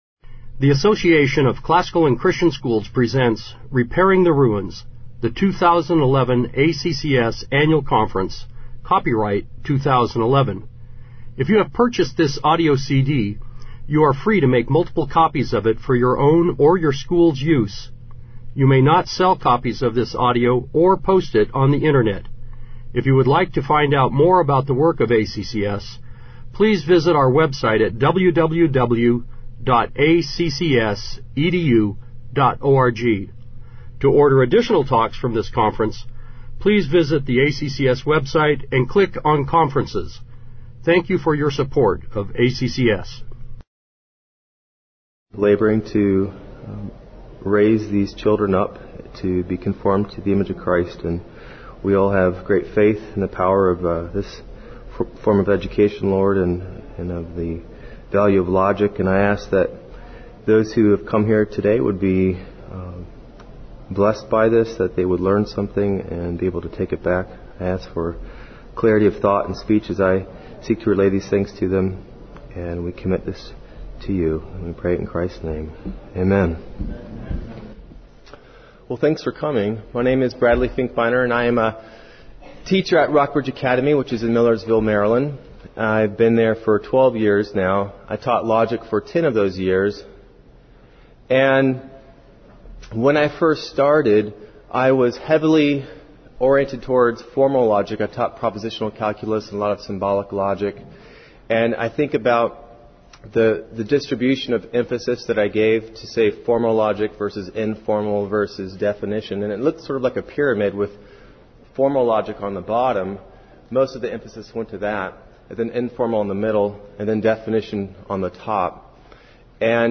2011 Workshop Talk | 1:00:23 | All Grade Levels, Logic
Speaker Additional Materials The Association of Classical & Christian Schools presents Repairing the Ruins, the ACCS annual conference, copyright ACCS.